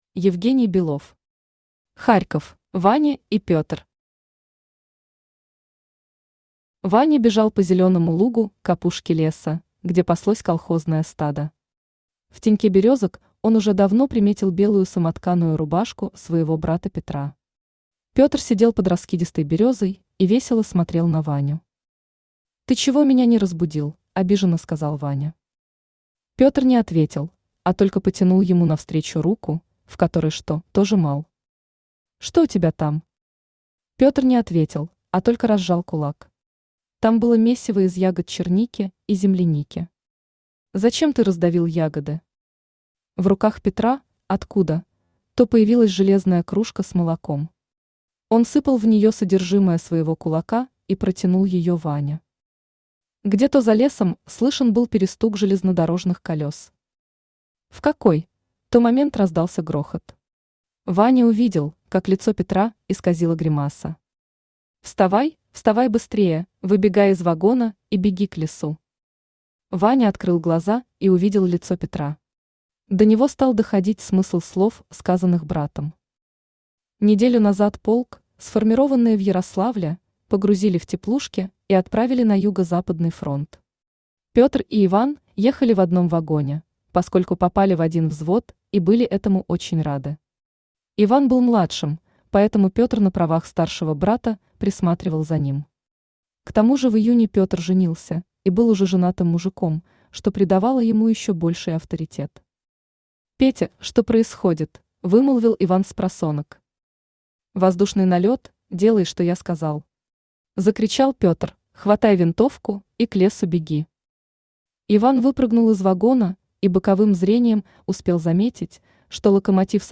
Аудиокнига Харьков | Библиотека аудиокниг
Aудиокнига Харьков Автор Евгений Владимирович Белов Читает аудиокнигу Авточтец ЛитРес.